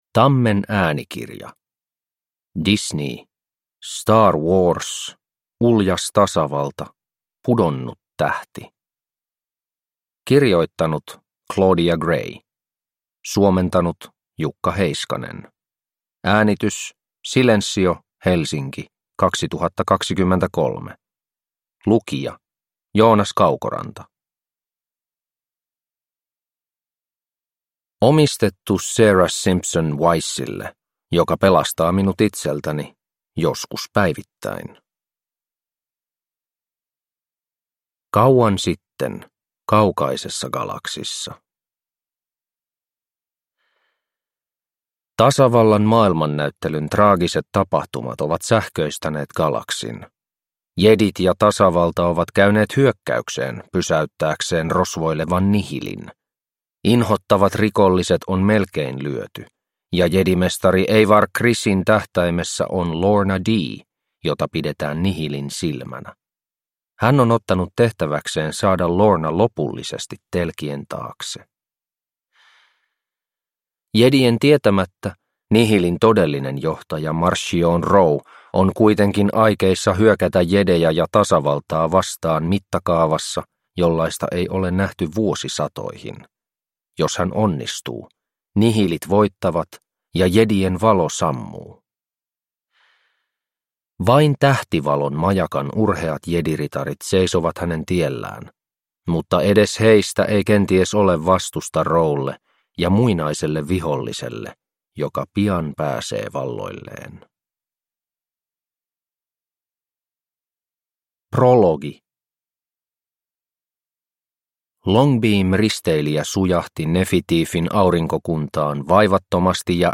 Star Wars Uljas tasavalta. Pudonnut tähti (ljudbok) av Claudia Gray